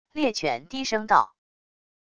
猎犬低声道wav音频